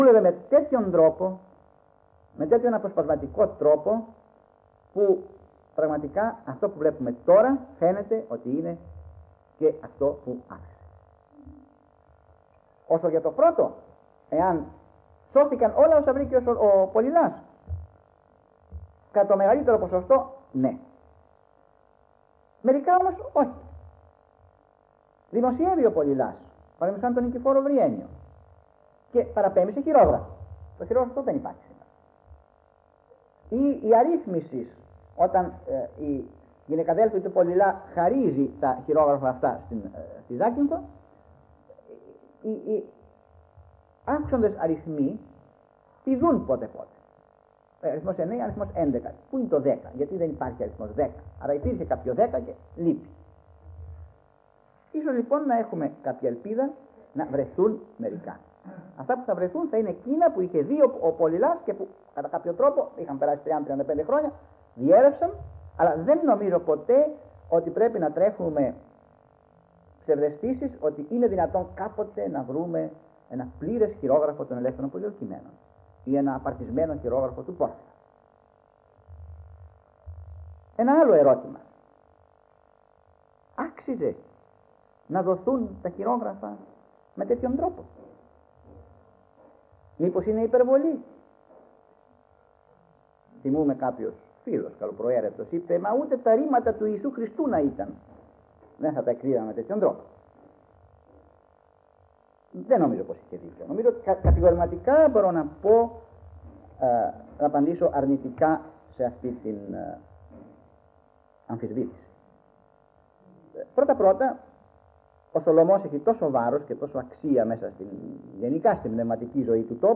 Εξειδίκευση τύπου : Εκδήλωση
Περιγραφή: Ομιλία